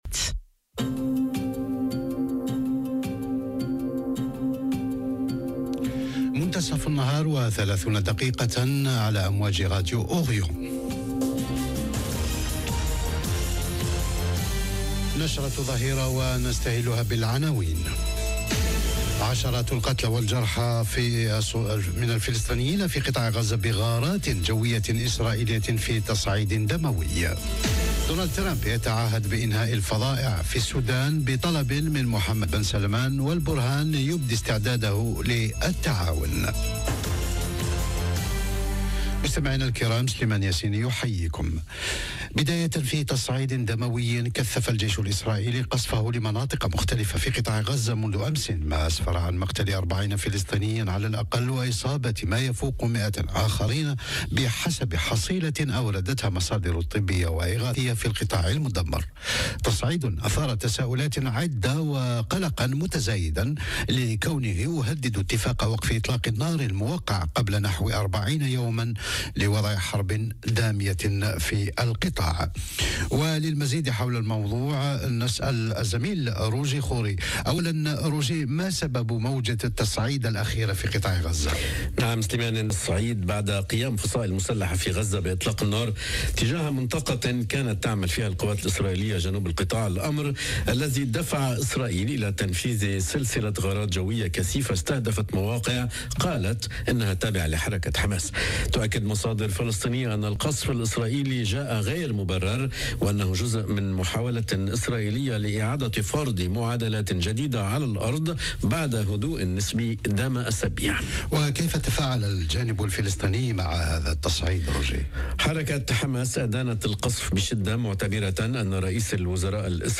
نشرة أخبار الظهيرة: عشرات القتلى والجرحى الفلسطنين في غرة بغارات جوية إسرائيلية ترامب يتعهد بإنهاء "الفظائع" في السودان بطلب من محمد بن سلمان والبرهان يبدي استعداده للتعاون - Radio ORIENT، إذاعة الشرق من باريس